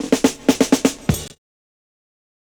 Index of /90_sSampleCDs/USB Soundscan vol.46 - 70_s Breakbeats [AKAI] 1CD/Partition B/19-094FILLS2
94FILLS06.wav